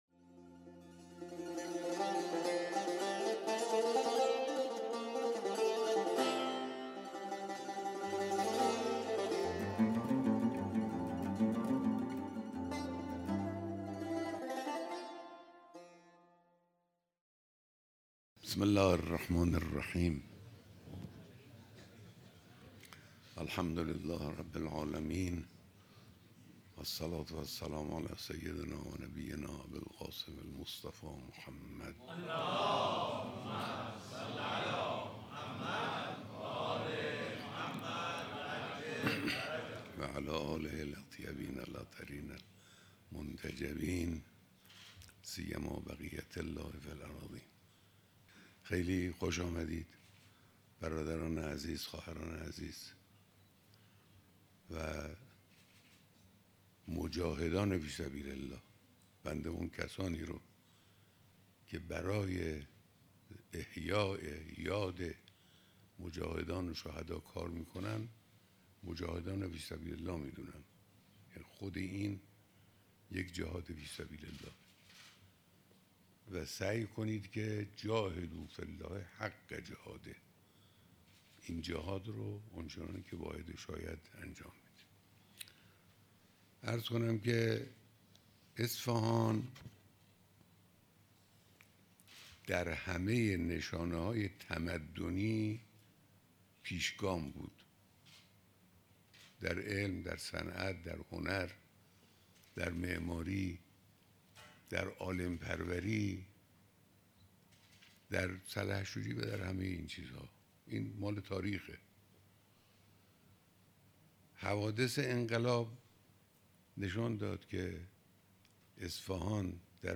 بیانات در دیدار دست اندرکاران برگزاری کنگره بزرگداشت 24 هزار شهید استان اصفهان